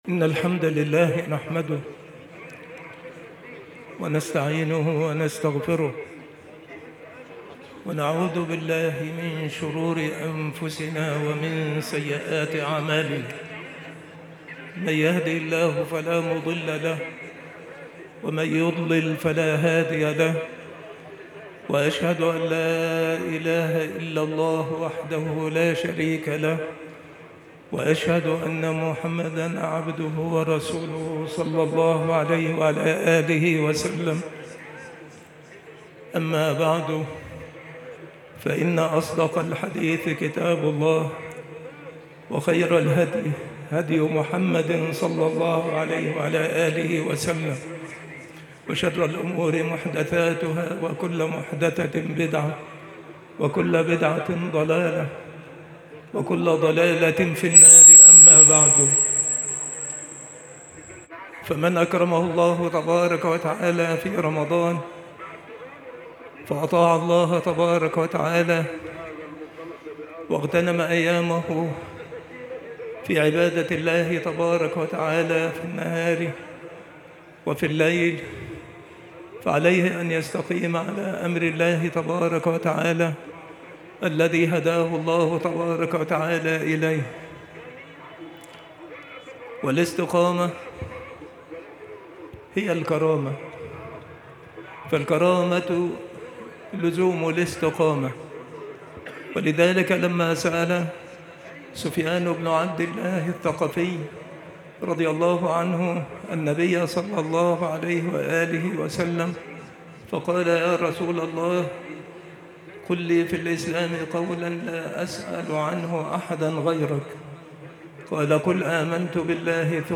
خطبة عيد الفطر لعام 1447هـ
خطب العيدين
مكان إلقاء هذه المحاضرة سبك الأحد - أشمون - محافظة المنوفية - مصر